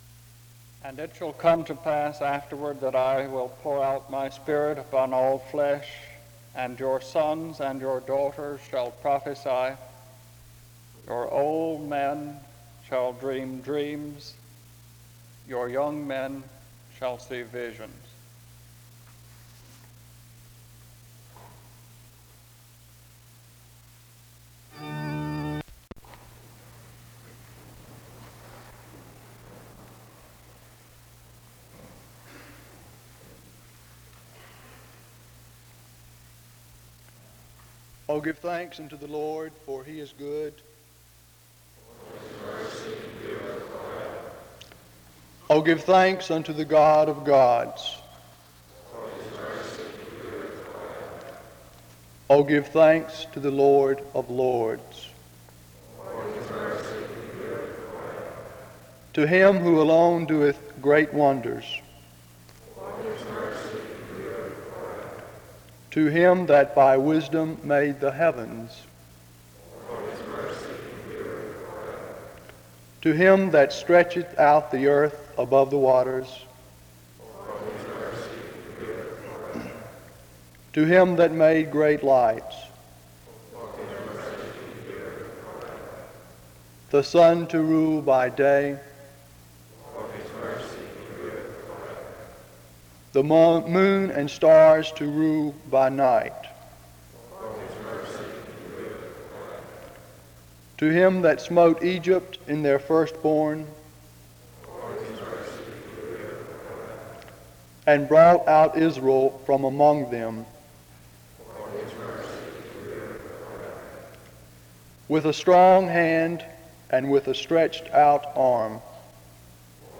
SEBTS Chapel - Student Prayer Service October 19, 1967
The service begins with an opening reading of scripture from 0:00-0:18. A responsive reading takes place from 0:38-3:46.
Those in chapel pray from 4:42-6:21.
A closing prayer is offered from 8:45-12:34.